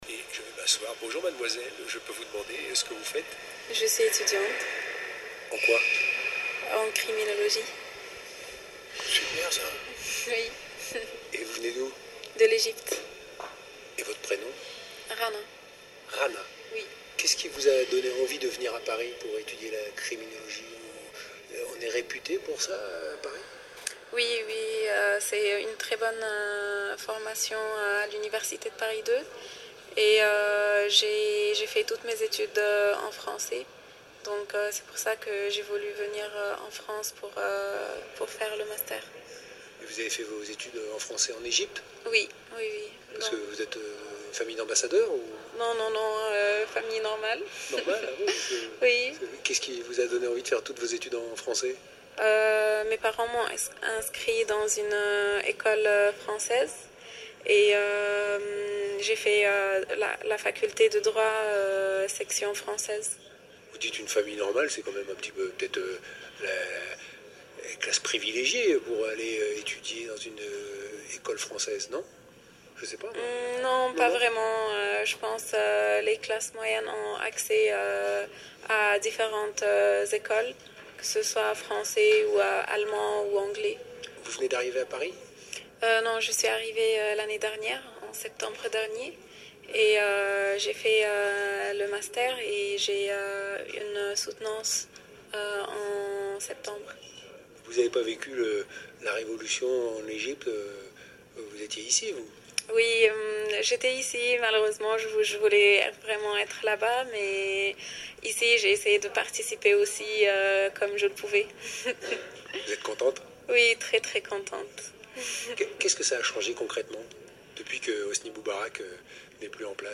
Un très joli témoignage, dans un français parfait.